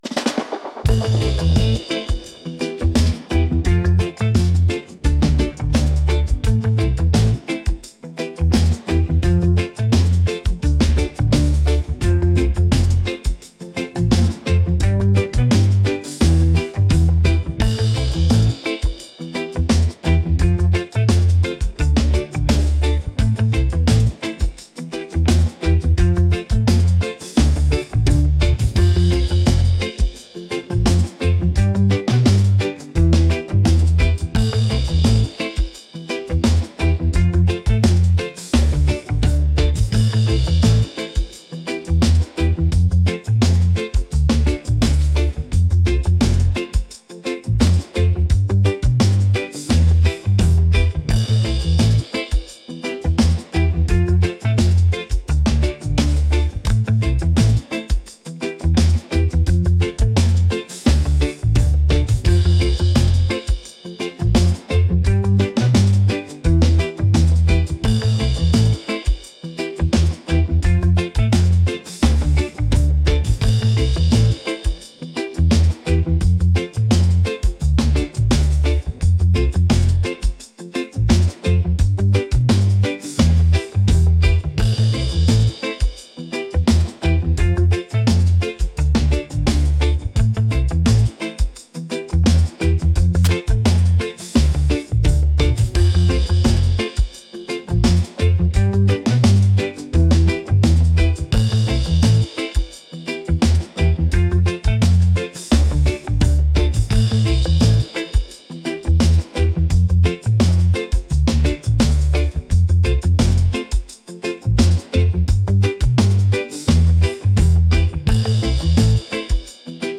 groovy | upbeat | reggae